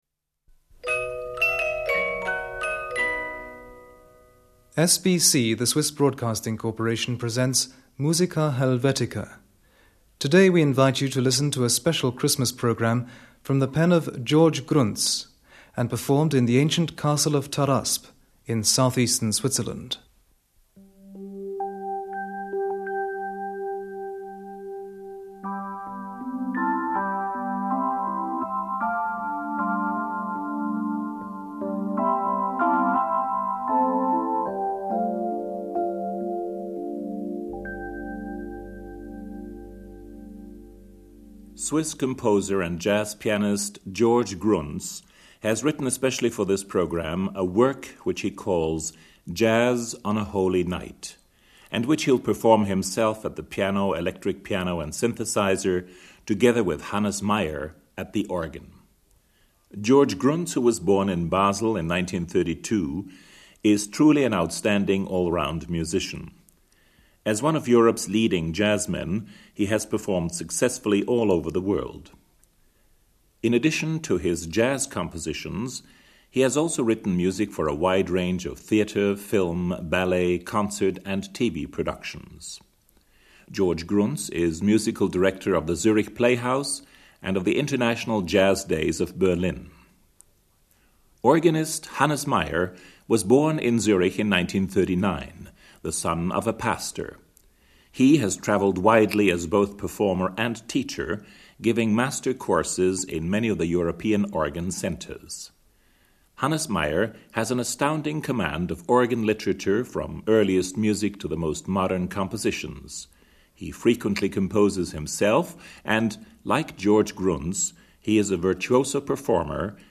keyboards.
organ. 2.